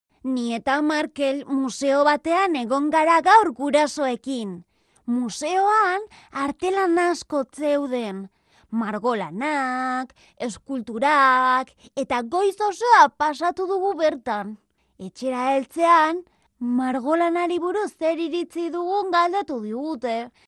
locutora euskera, basque voice over
voz de niña